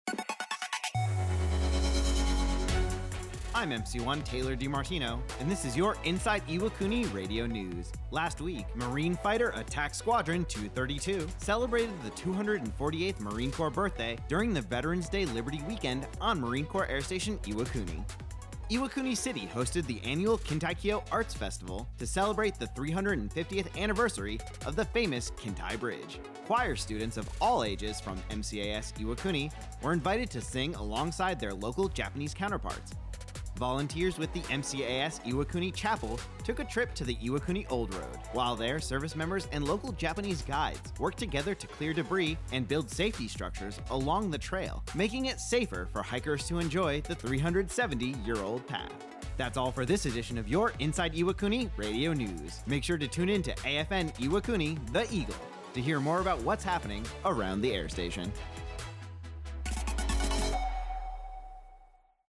Inside Iwakuni Radio Newscast covering the Kintaikyo art festival